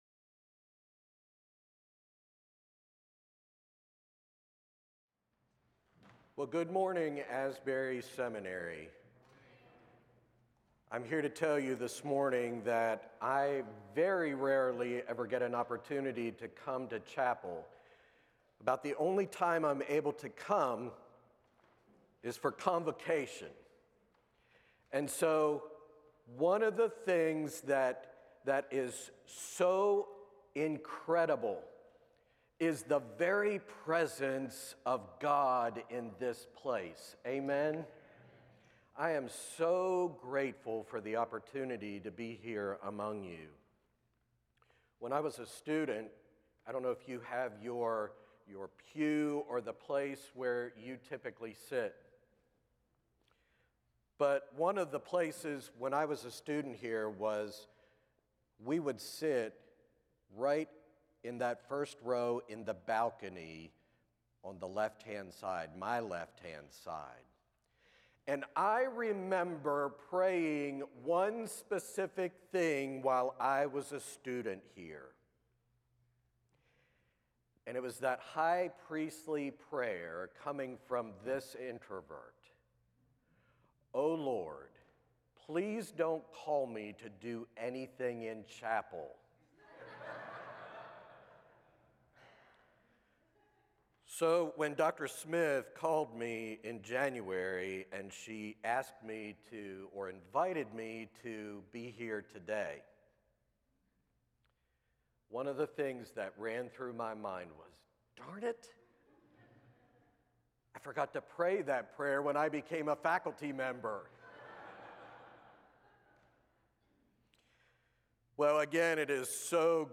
The following service took place on Tuesday, April 14, 2026.